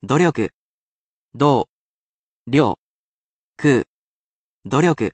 doryoku